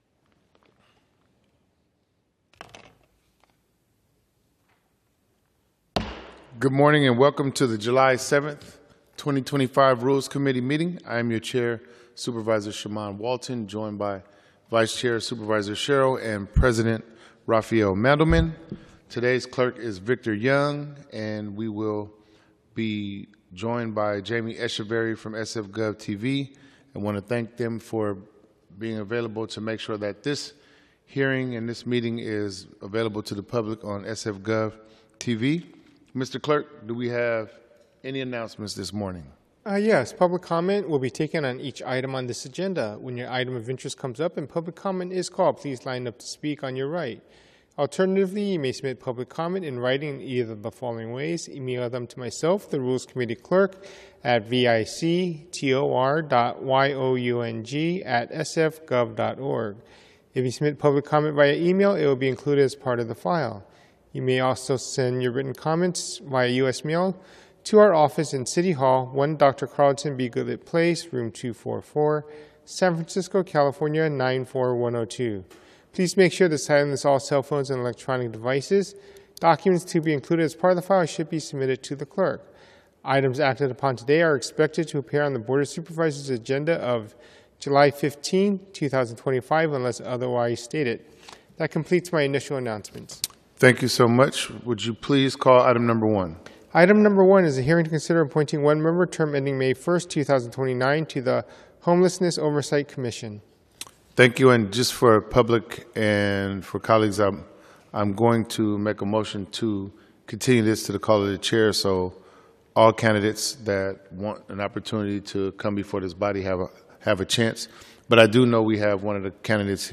Rules Committee - Regular Meeting - Jul 07, 2025